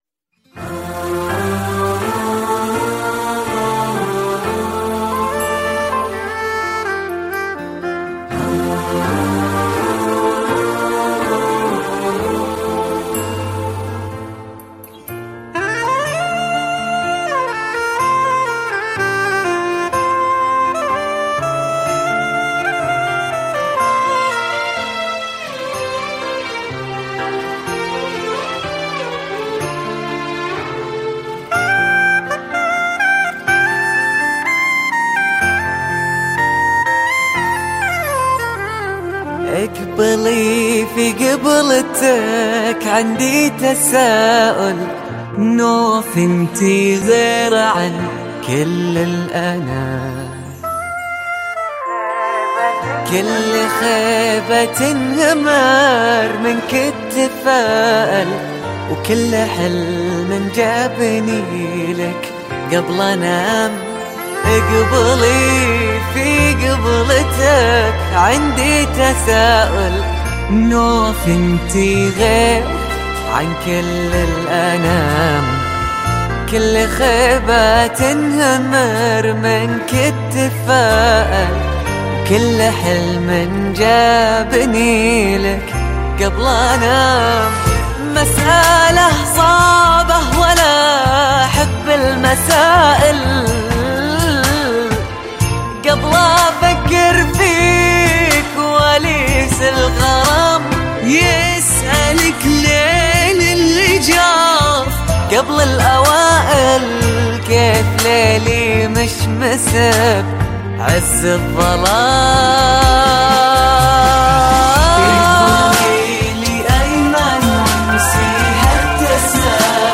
زفات بدون موسيقي